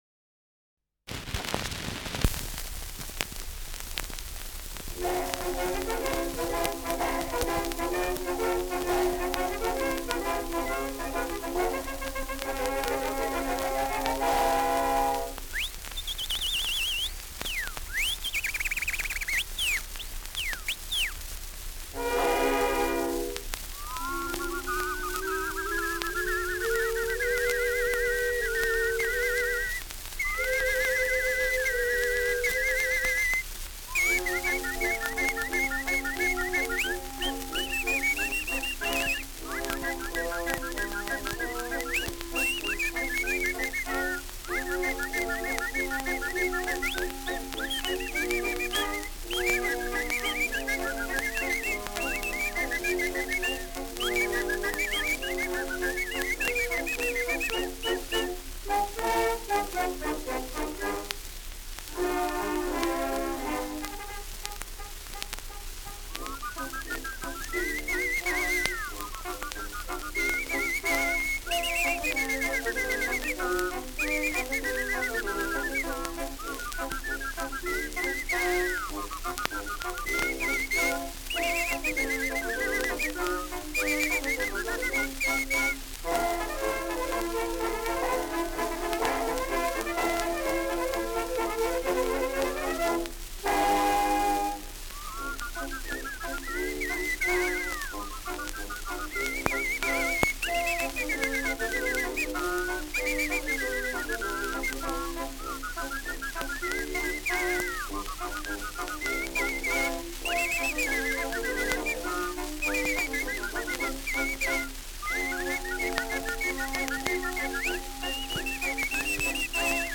VÖGEL & VOLKSMUSIK
Die Forschungsstelle für fränkische Volksmusik präsentiert die historische Aufnahme "Die beiden Grasmücken" Kunstpfeifer mit Orchesterbegleitung (aufgenommen zwischen 1909 und 1930).
Die_beiden_Grasmuecken_Kunstpfeifer.mp3